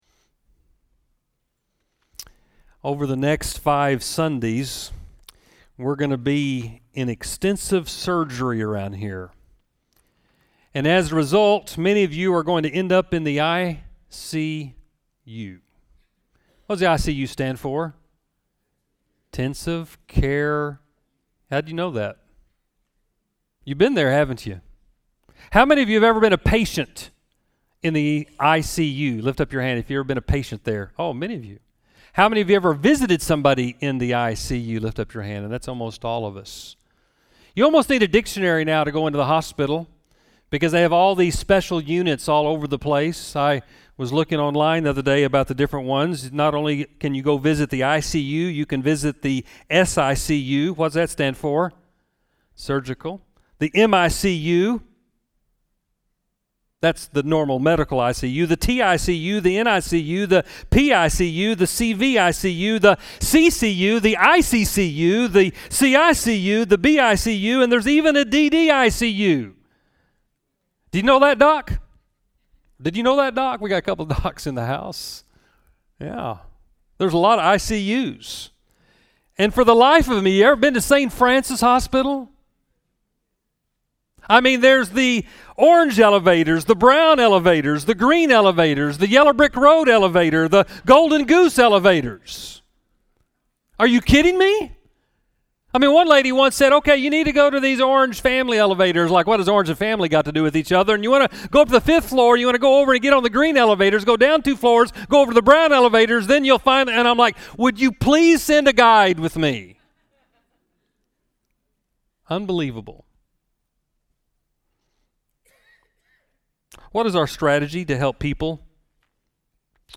Sermons | Central Baptist Church Owasso